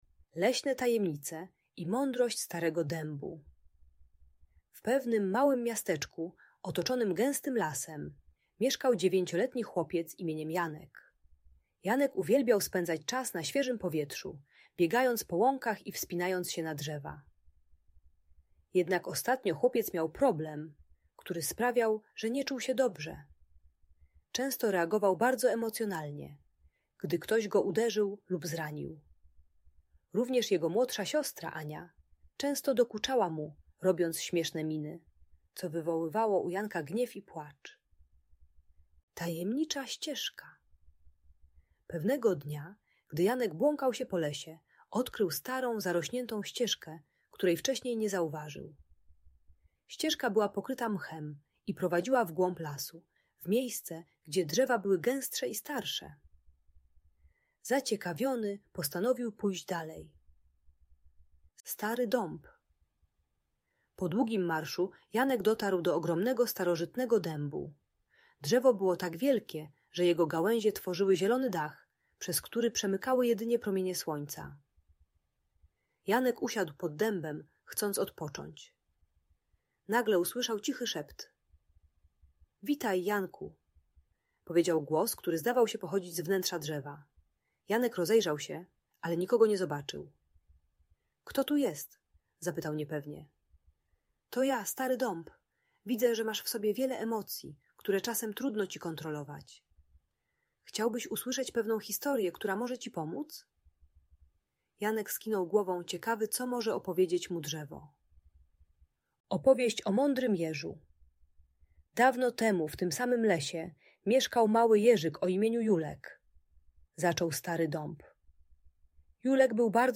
Historia uczy techniki głębokiego oddychania, liczenia do dziesięciu i wizualizacji spokojnego miejsca, gdy dziecko czuje gniew. Audiobajka o radzeniu sobie ze złością i prowokacjami rodzeństwa.